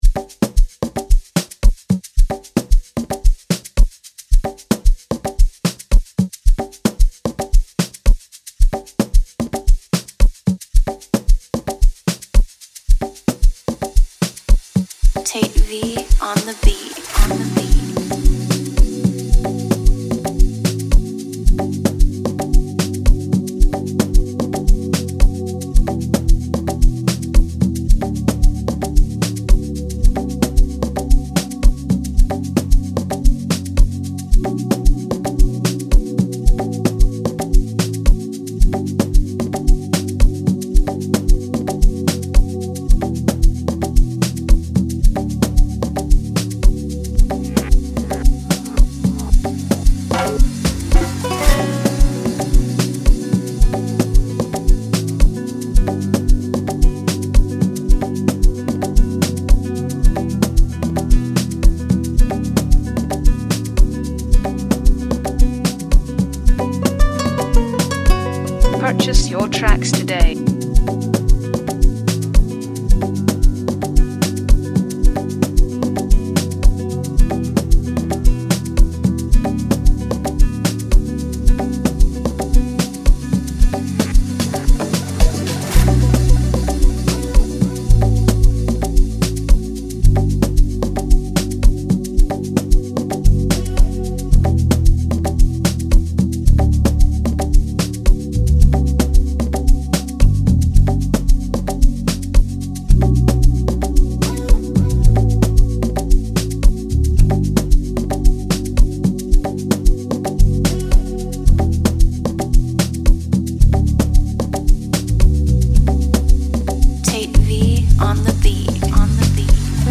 Energetic, Positive, Sexy, Soulful